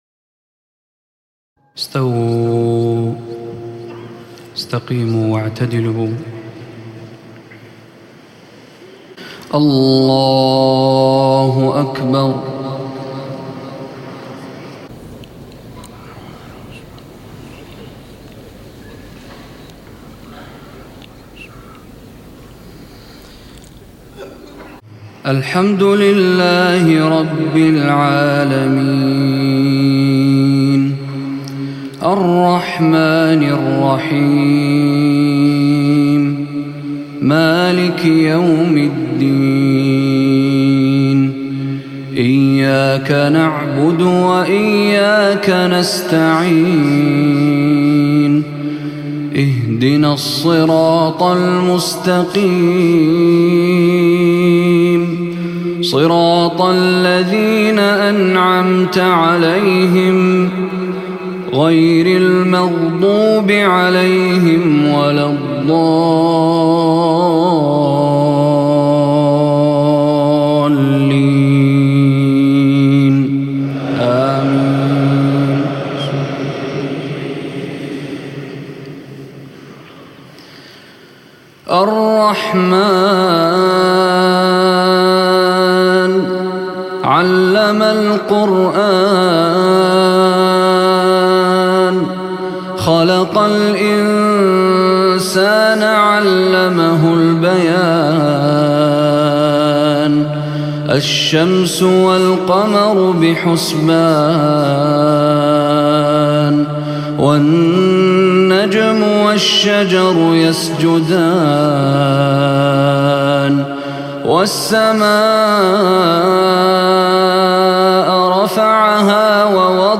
Surat Al Rahman Receiter Meshary Rashed
Quran recitations